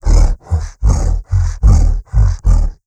MONSTERS_CREATURES
MONSTER_Exhausted_13_mono.wav